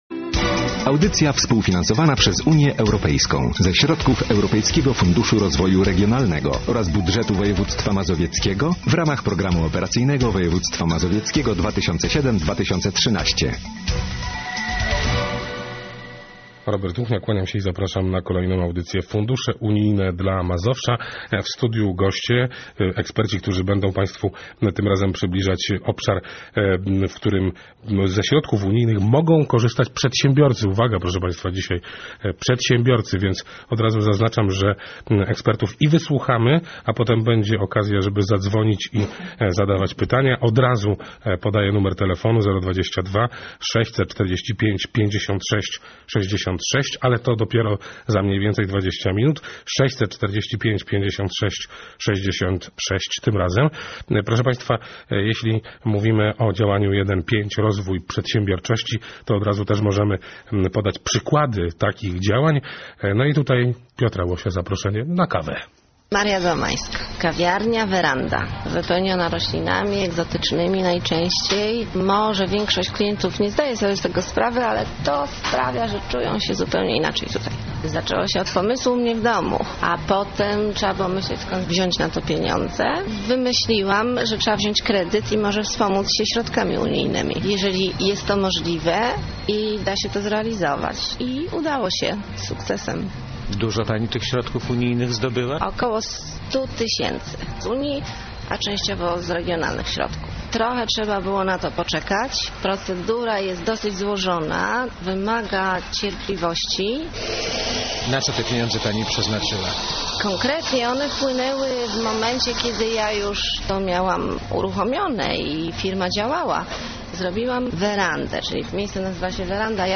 W piątek 12 września 2008 r. wyemitowana została audycja poświęcona działaniu 1.5. Rozwój przedsiębiorczości w ramach Regionalnego Programu Operacyjnego Województwa Mazowieckiego.